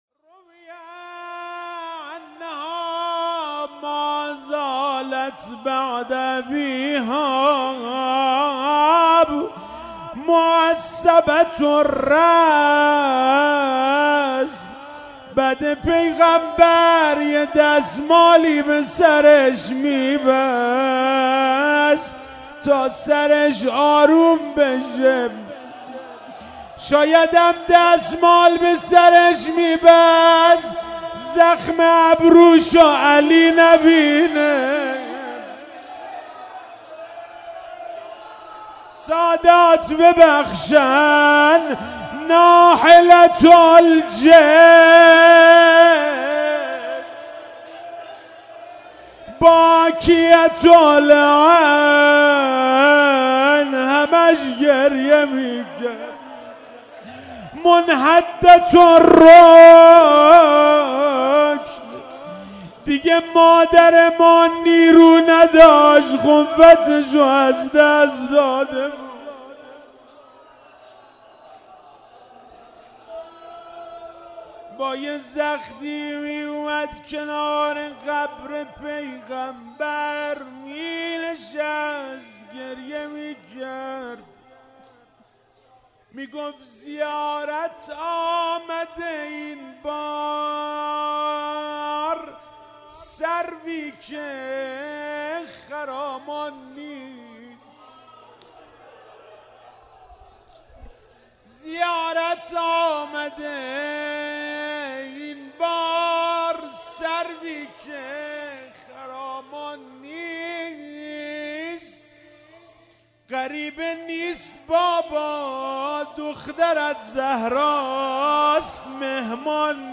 فاطمیه دوم 96- شب اول - روضه - بعد پیغمبر یه دستمالی به سرش می بست